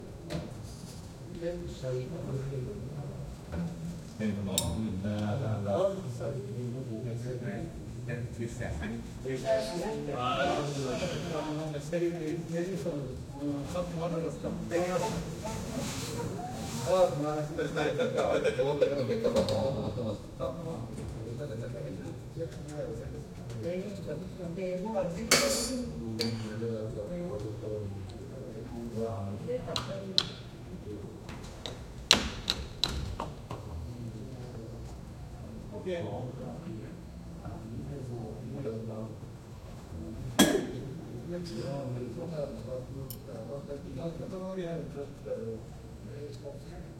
Create a medieval tavern soundscape with lively chatter, cheers, laughter, tankard clinking, footsteps on wooden floors, a crackling fireplace, and a bard playing softly.
create-a-medieval-tavern--jodvmowm.wav